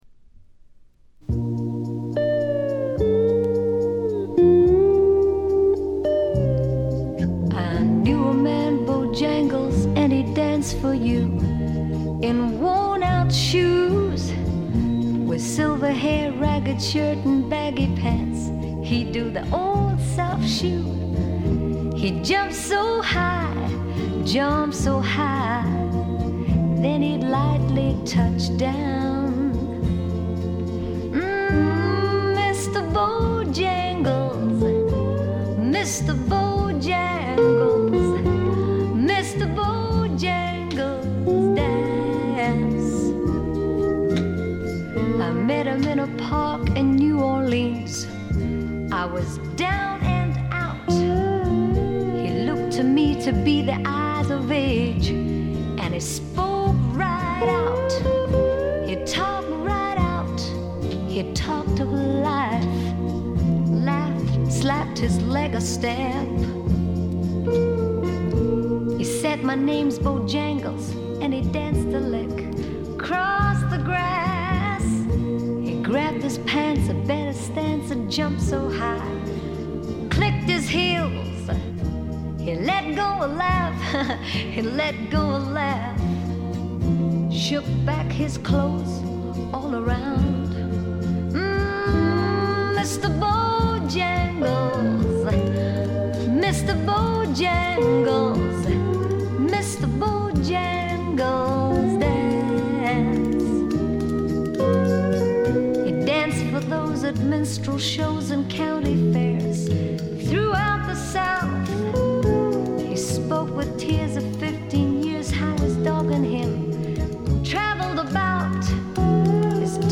部分試聴ですが、ごくわずかなノイズ感のみ。
試聴曲は現品からの取り込み音源です。